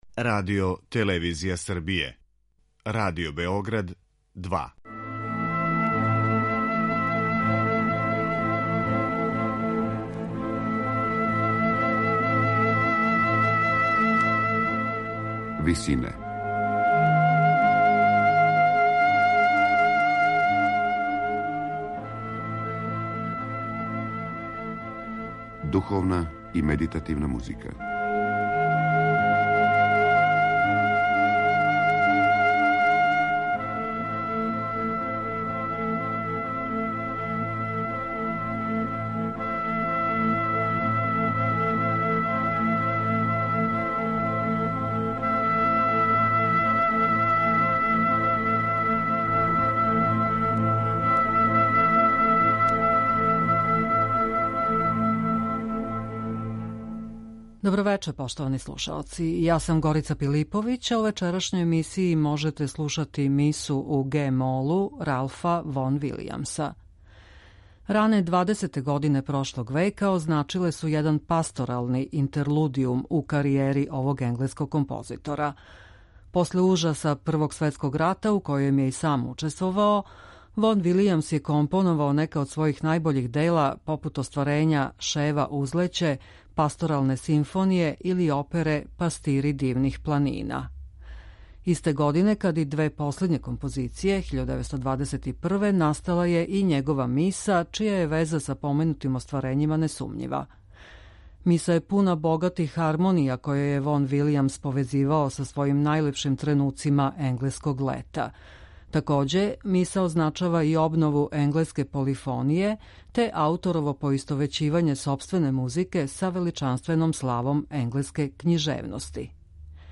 Миса је пуна богатих хармонија које је Вон-Вилијамс повезивао са својим најлепшим тренуцима енглеског лета.